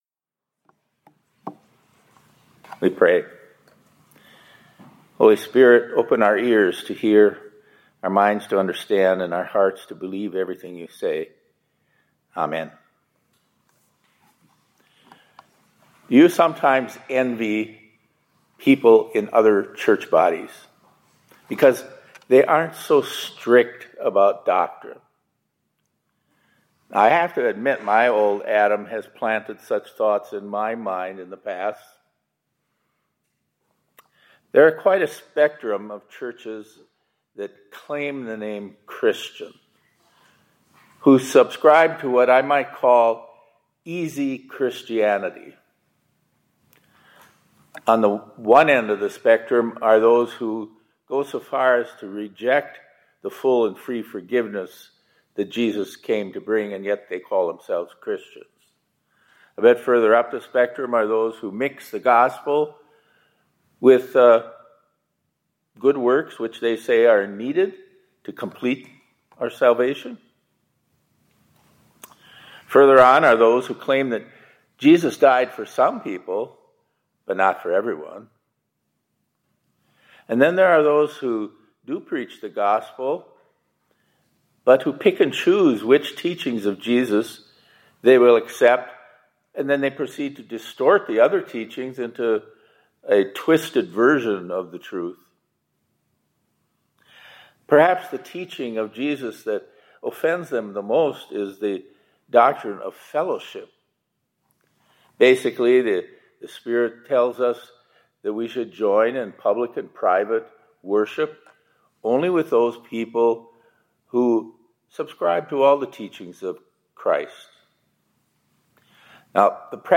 2025-11-24 ILC Chapel — Christianity is Hard . .…